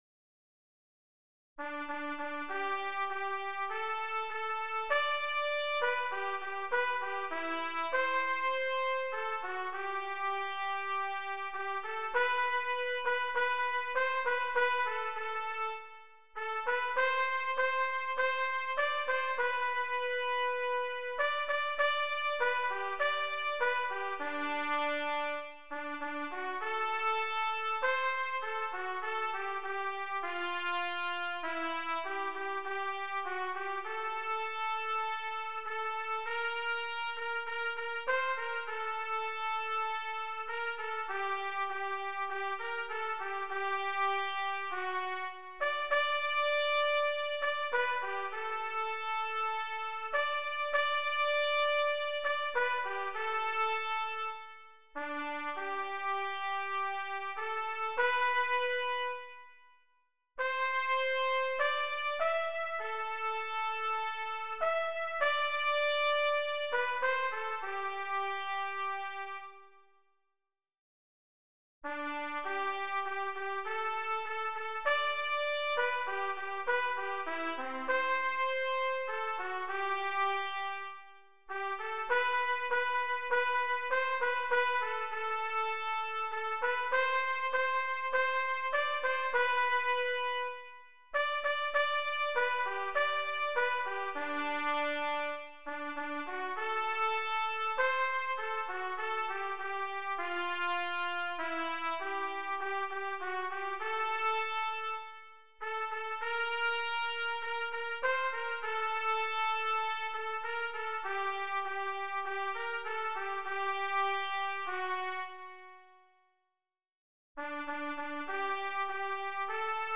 Compositeur : Claude Joseph Rouget de Lisle Chant traditionnel La R�publique fut proclam�e, pour la premi�re fois, en France, le 21 septembre 1792 par la Convention Nationale.